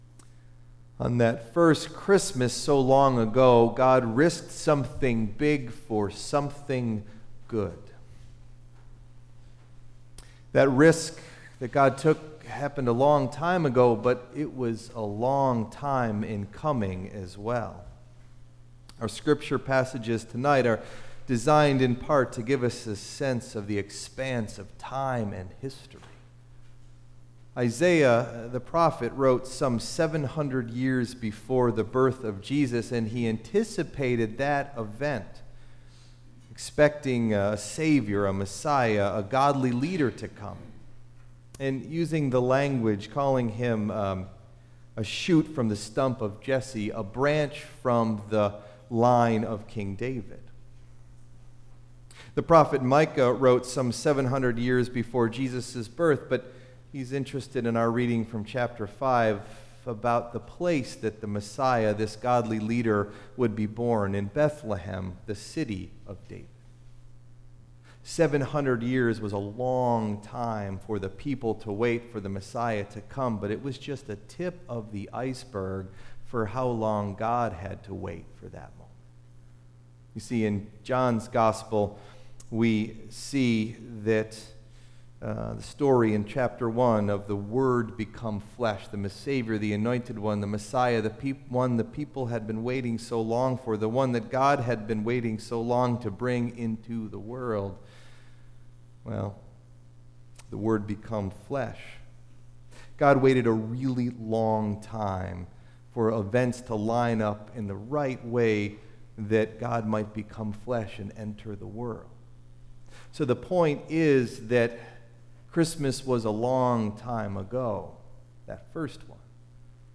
Date: December 24th, 2016 (Christmas Eve – 10PM Service)
Message Delivered at: The United Church of Underhill (UCC and UMC)